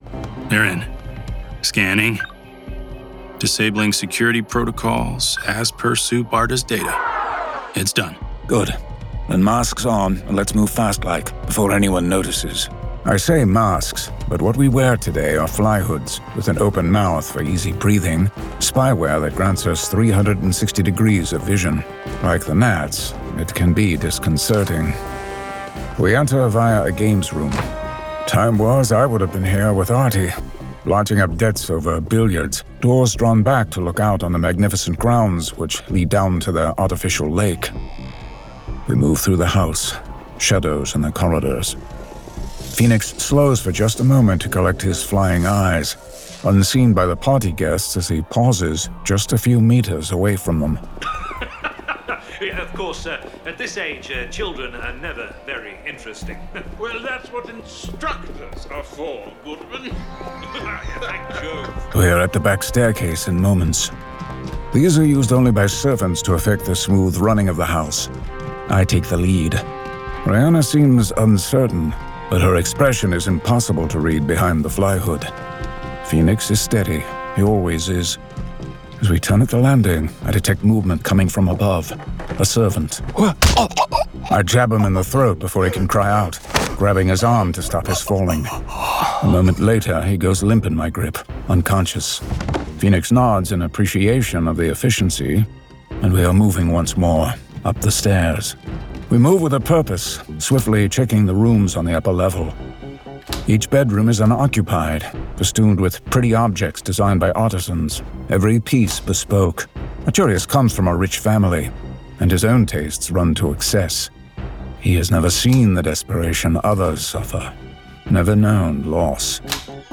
Full Cast. Cinematic Music. Sound Effects.
Adapted from the graphic novel and produced with a full cast of actors, immersive sound effects and cinematic music.